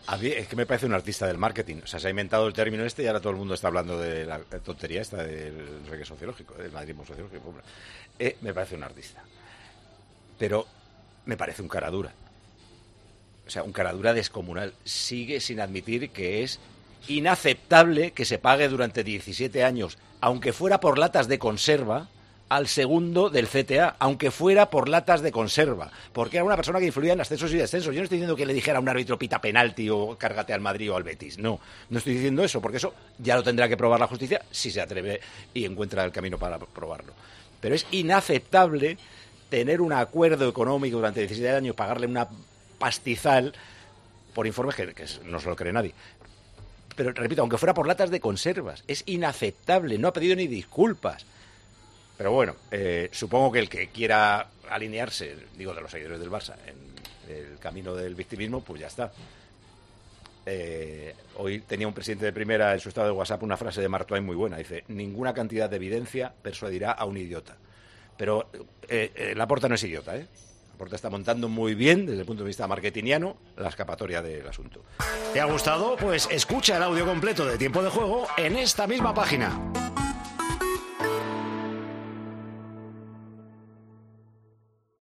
En respuesta a ese nuevo término de Laporta y analizando las últimas noticias, el director y presentador de Tiempo de Juego, Paco González, respondió al dirigente culé durante los primeros minutos del programa de este sábado de Tiempo de Juego: "Aunque fuera por latas de conserva"